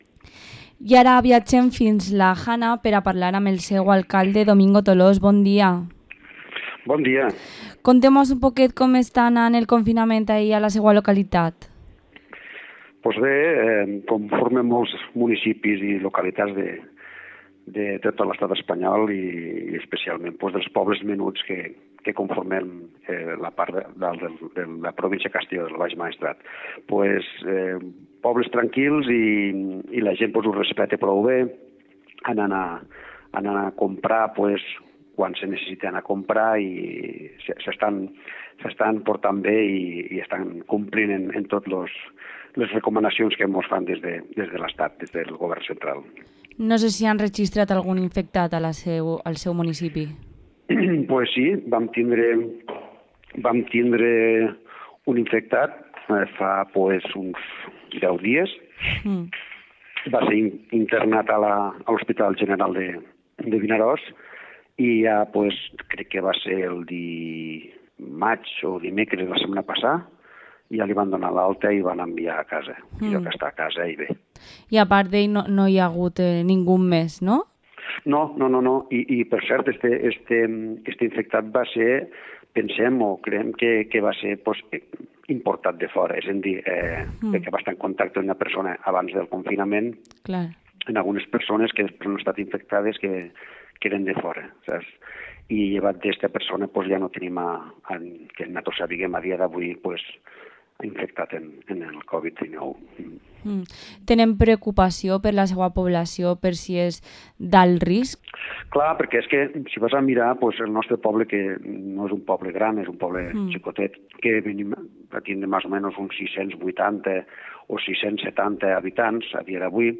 Entrevista a Domingo Tolós, alcalde de La Jana